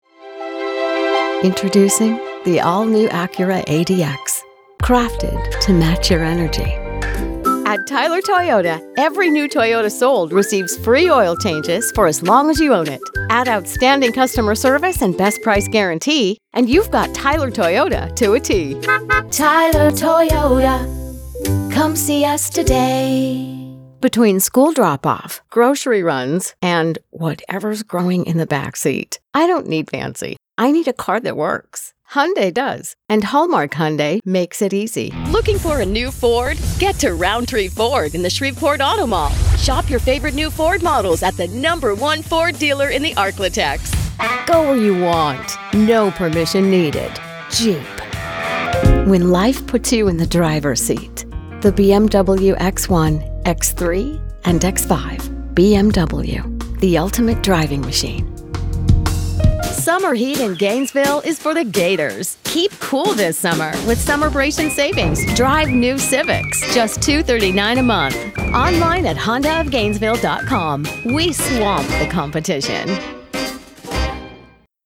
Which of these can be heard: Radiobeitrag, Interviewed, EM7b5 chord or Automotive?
Automotive